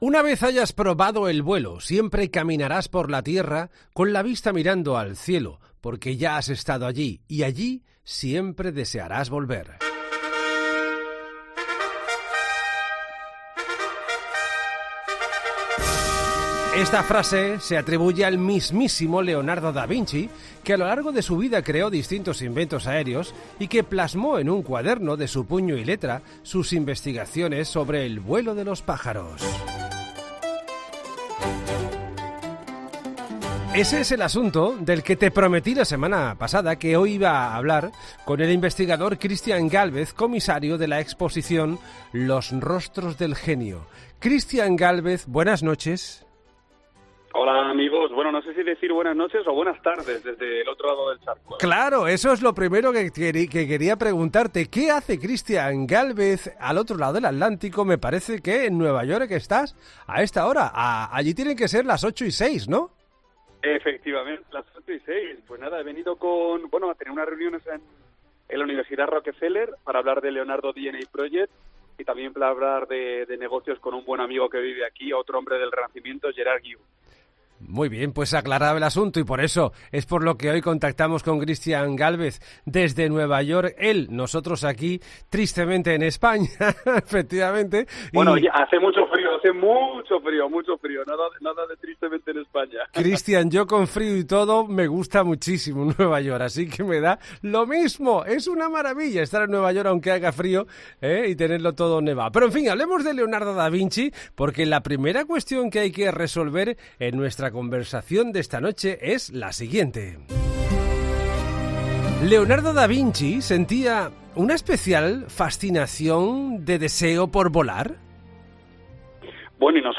En 'La Noche de COPE', Christian Gálvez nos adentra en los estudios e inventos de Leonardo relacionados con el vuelo de los pájaros.